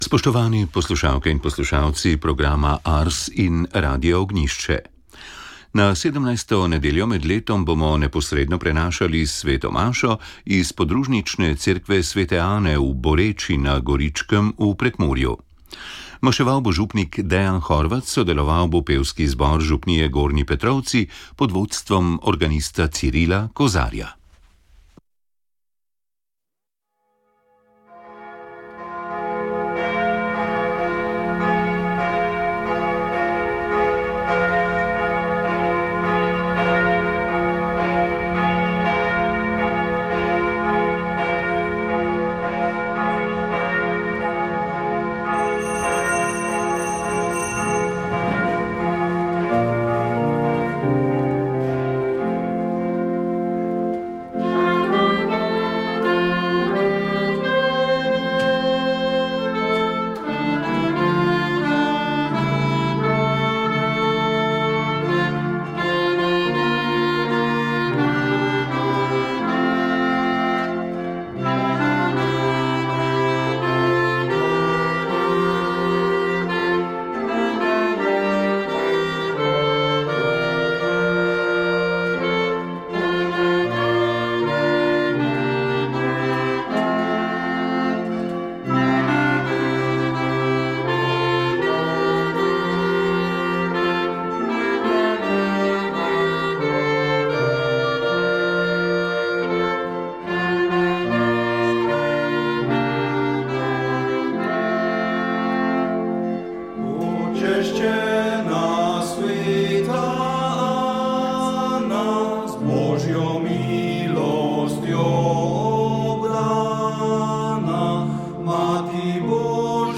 Sv. maša iz stolne cerkve sv. Nikolaja v Murski Soboti 25. 7.